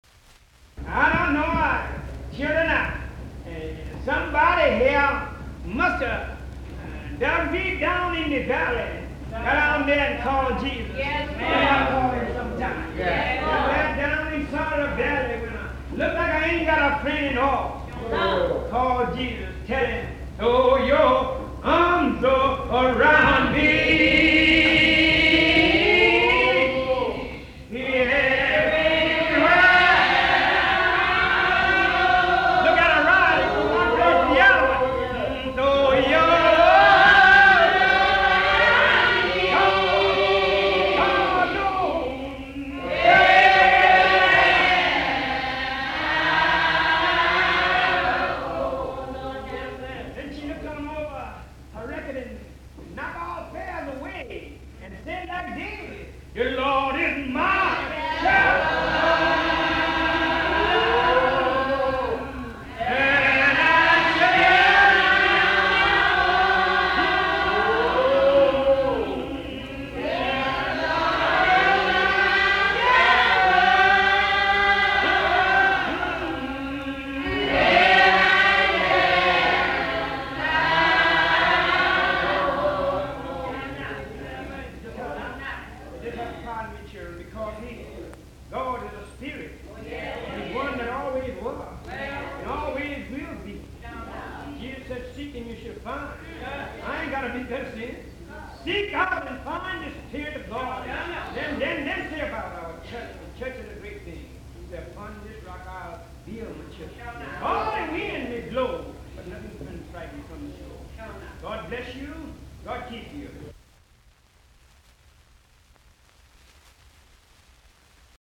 Music from the south : field recordings taken in Alabama, Lousiana and Mississippi.